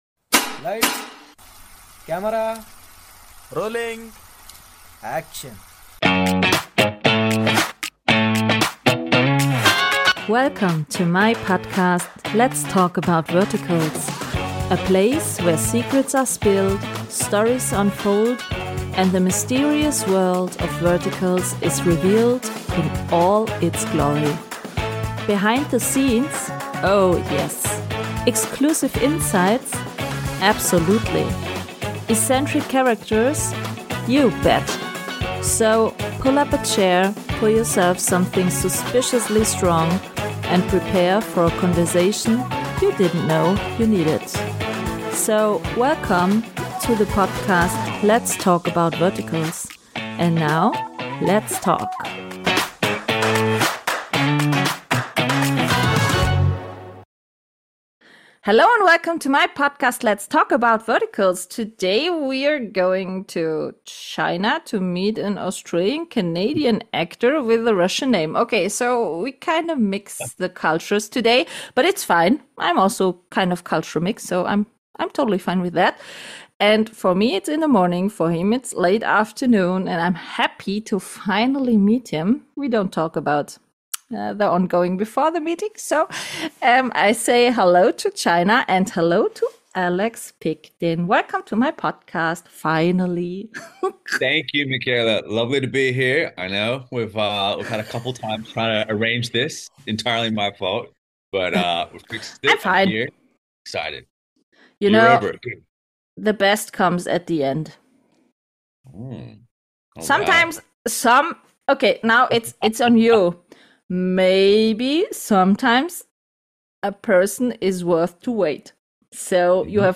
In this episode, I sit down for a morning chat (German time )